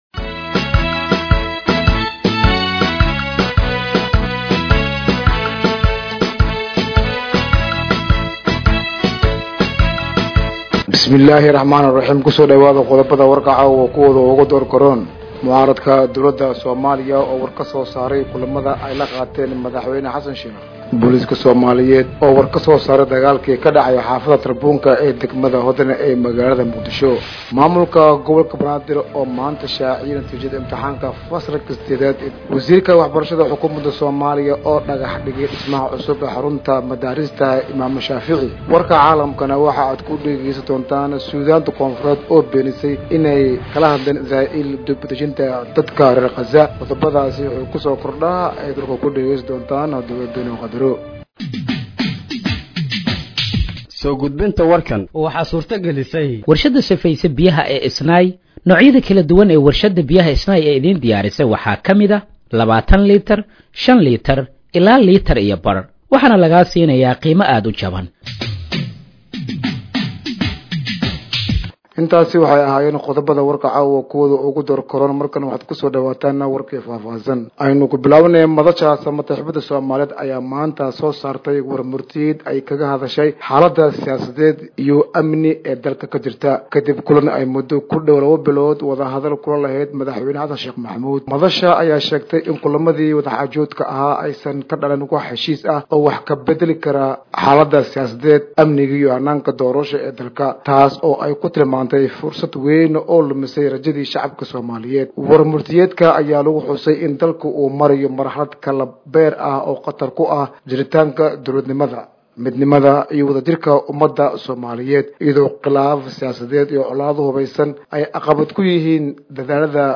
Dhageeyso Warka Habeenimo ee Radiojowhar 14/08/2025